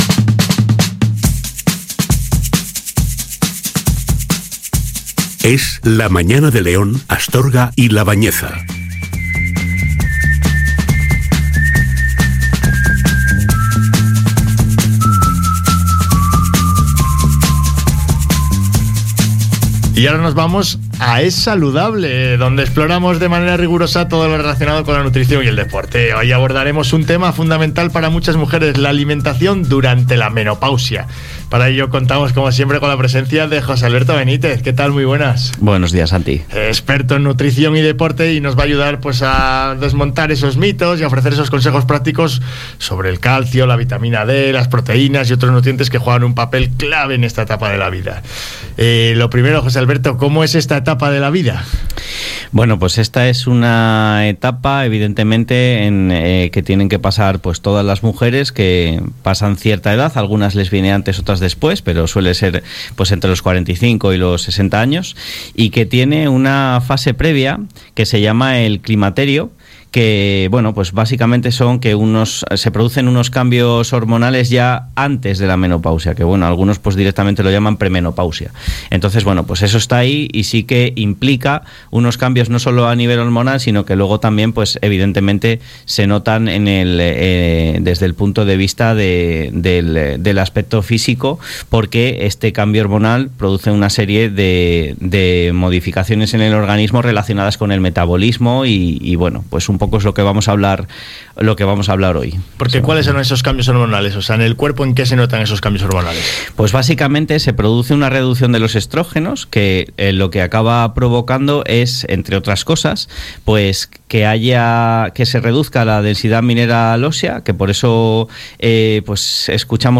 Hoy os traigo el tricentésimo quincuagésimo novenoprograma de la sección que comenzamos en la radio local hace un tiempo y que hemos denominado Es Saludable, en el programa Es la Mañana de León, Astorga y La Bañeza en EsRadio.